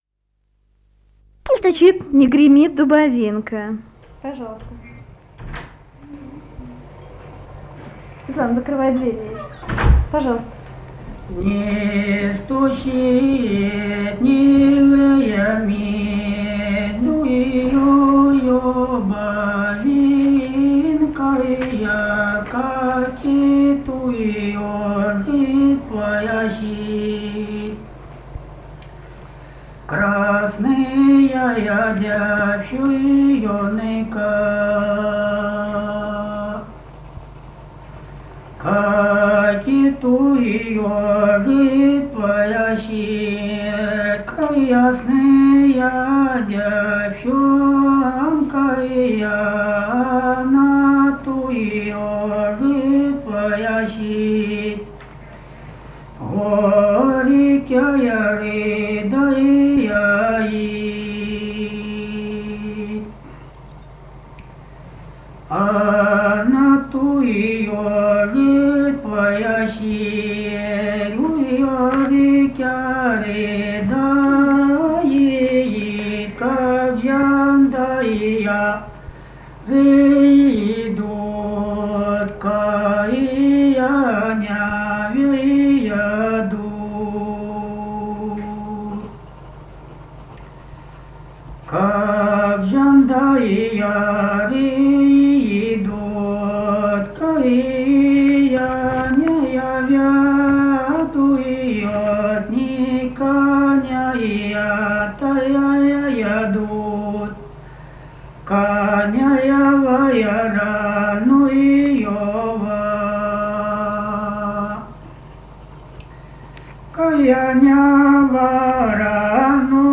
полевые материалы
Ставропольский край, с. Бургун-Маджары Левокумского района, 1963 г. И0728-01